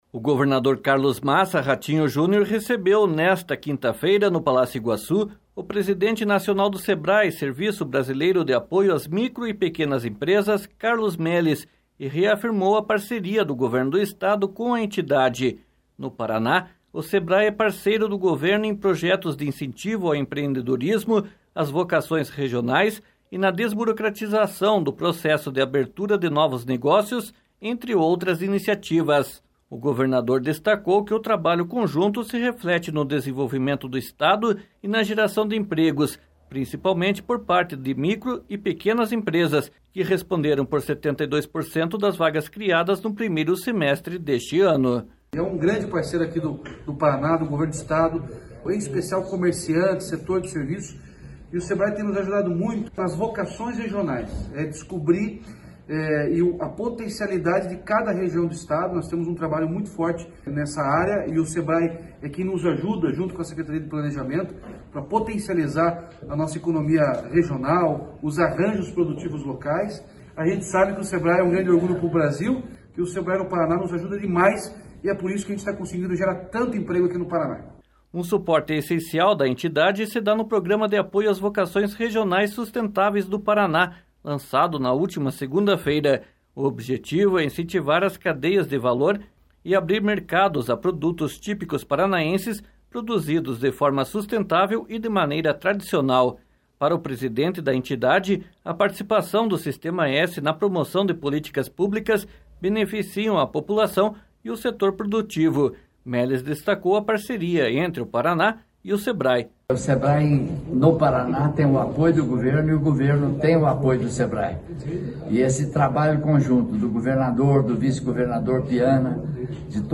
//SONORA RATINHO JUNIOR//